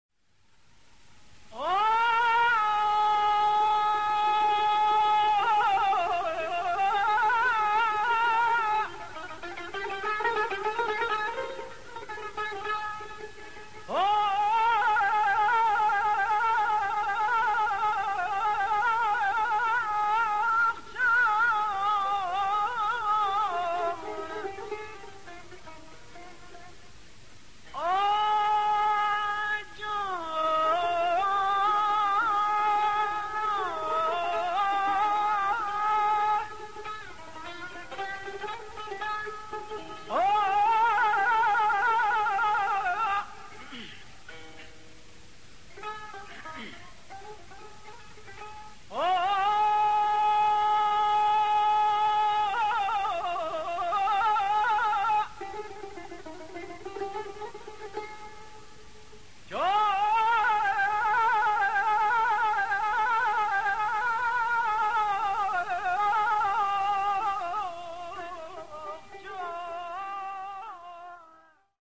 تار
آواز